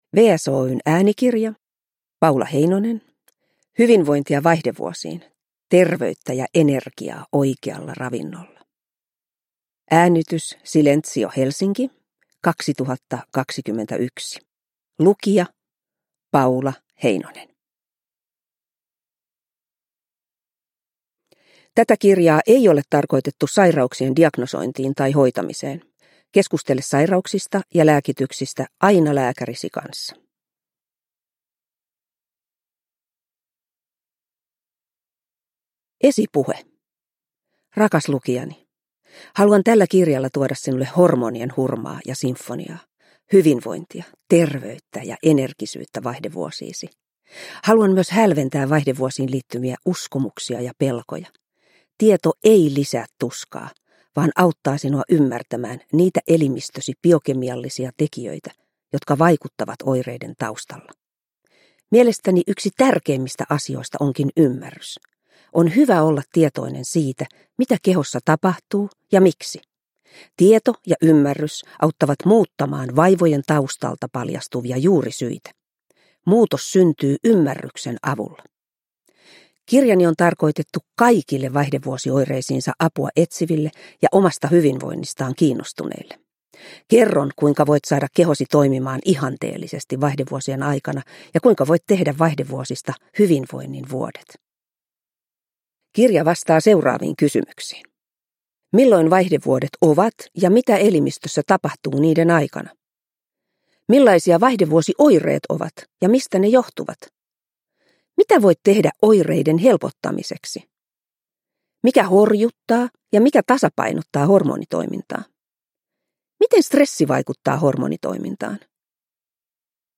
Hyvinvointia vaihdevuosiin – Ljudbok – Laddas ner